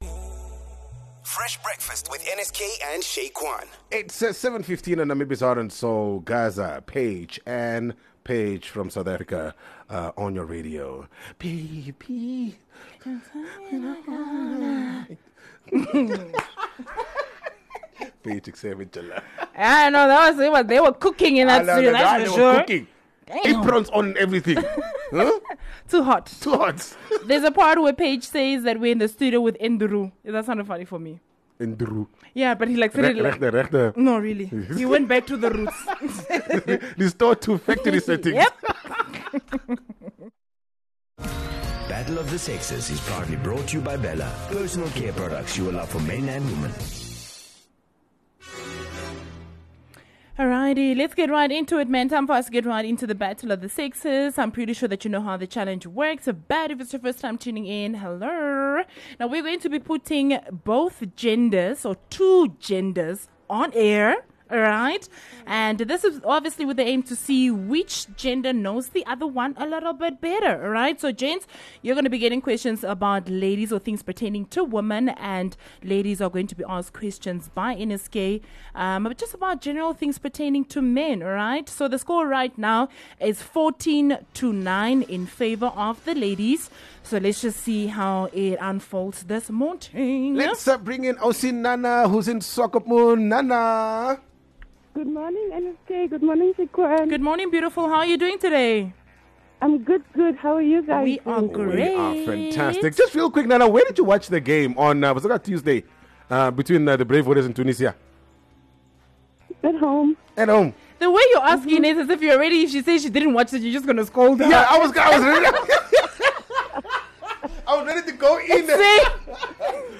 Battle of the Sexes is probably the most dramatic game show on Namibian radio. This is the bit where we have both genders on air with the aim to see which knows more about the other. So we will ask the gents questions about the ladies and ladies…we will ask questions about the gents!